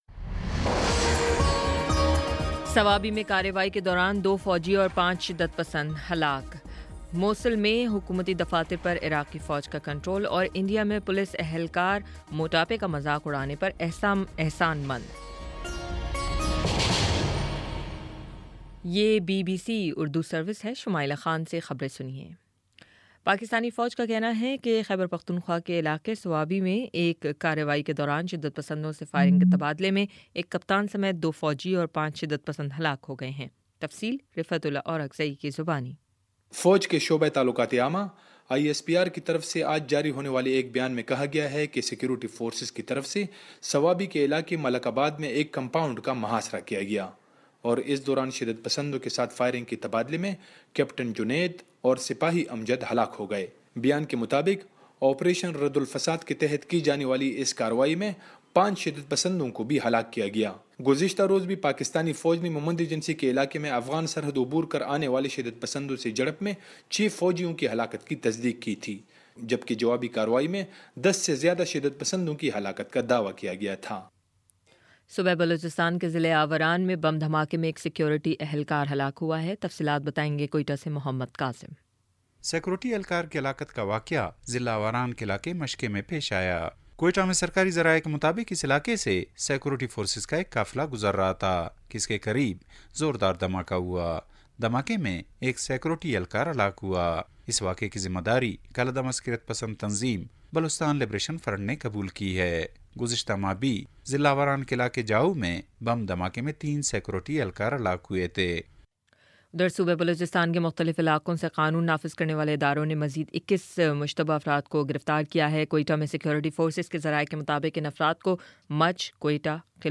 مارچ 07 : شام سات بجے کا نیوز بُلیٹن